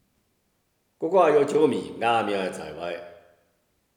25鸹鸹鹞叫鸣